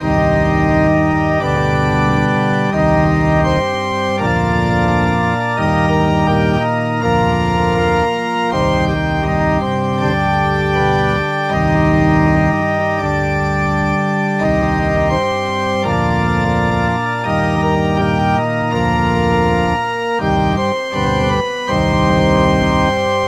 christus_ressurrexit-instrumental_organ.mp3